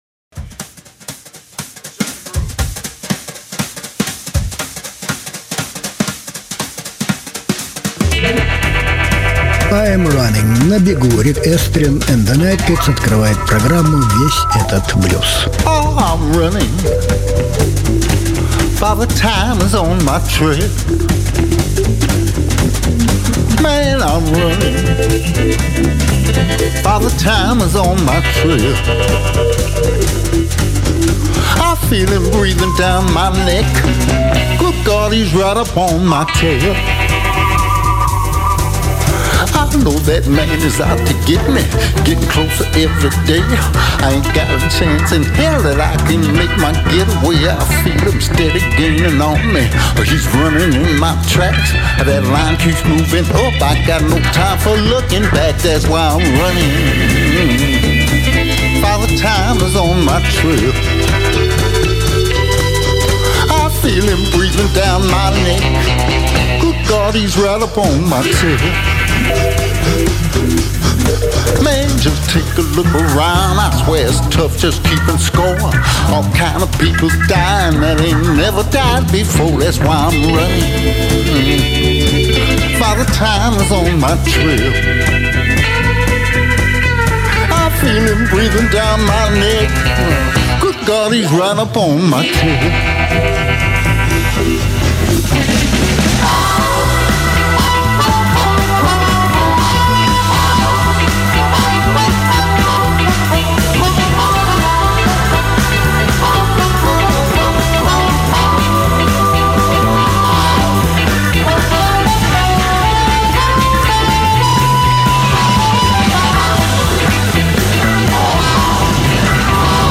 мастер игры на губной гармонике.
Жанр: Блюз